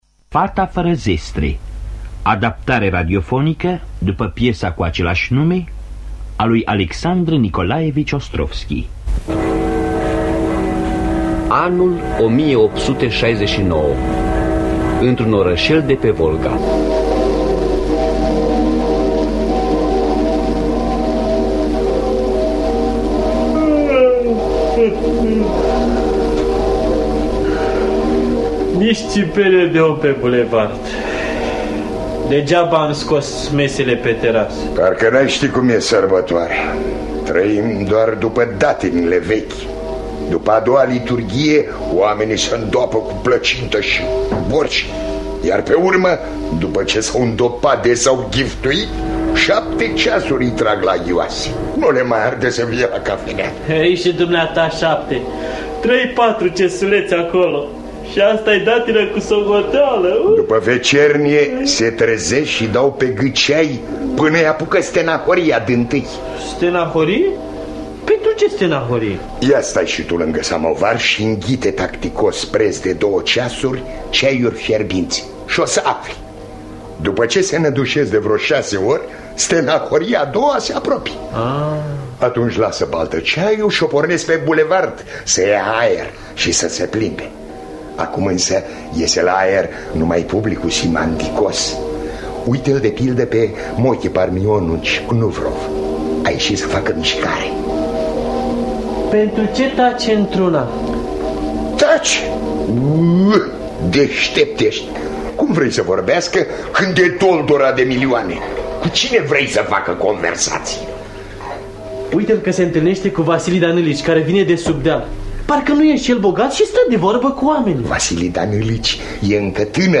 Adaptarea radiofonică de Radu Beligan.